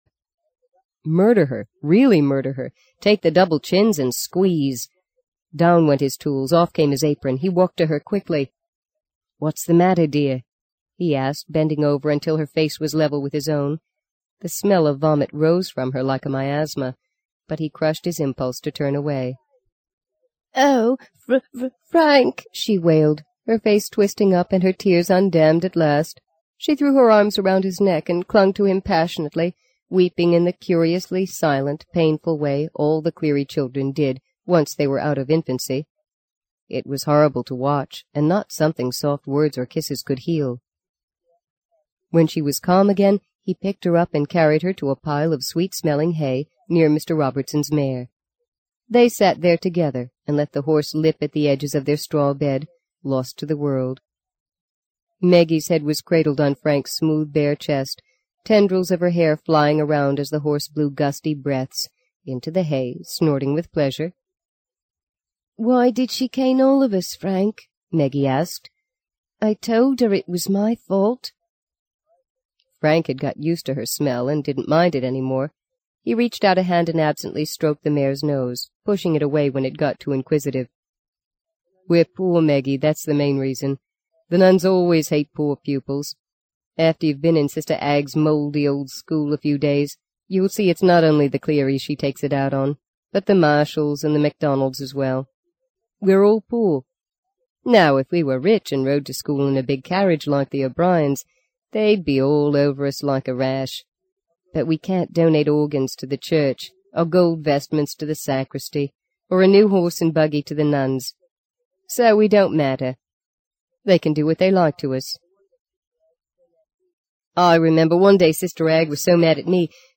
在线英语听力室【荆棘鸟】第二章 11的听力文件下载,荆棘鸟—双语有声读物—听力教程—英语听力—在线英语听力室